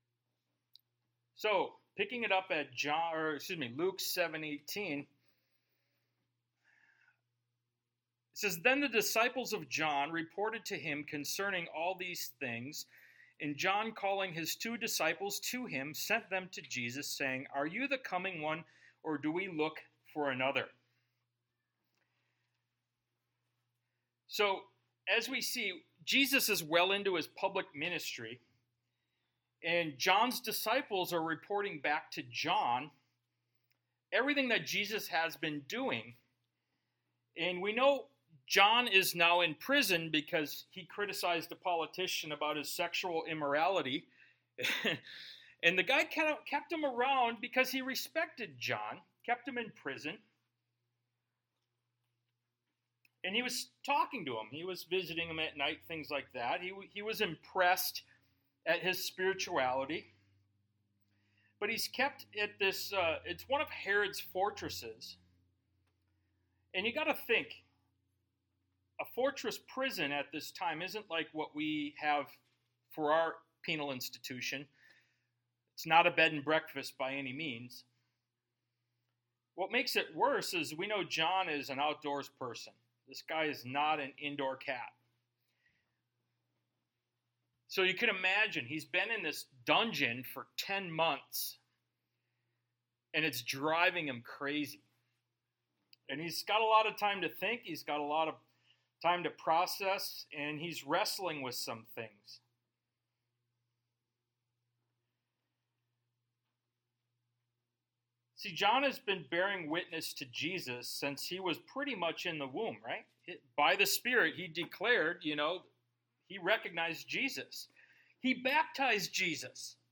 Ministry of Jesus Service Type: Sunday Morning « “Jesus is Life” Ministry of Jesus Part 23 “Are You Burdened?”